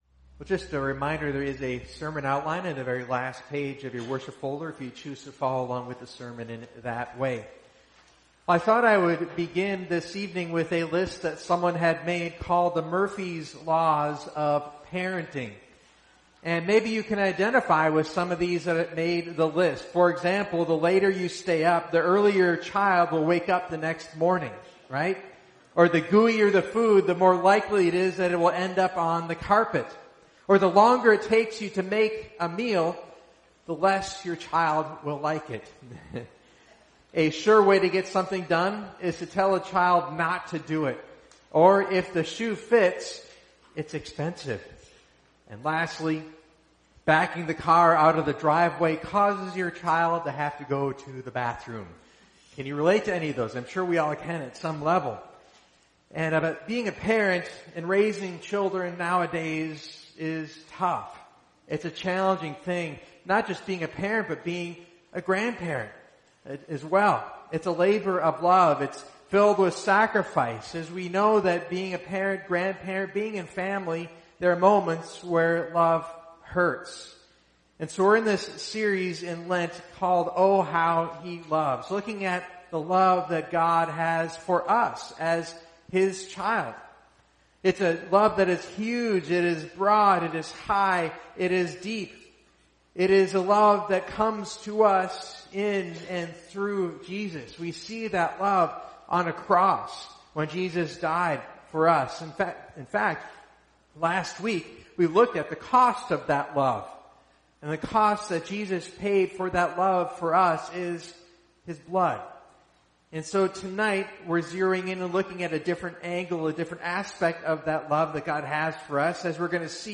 Join us for our Lenten series each Wednesday — O, How He Loves!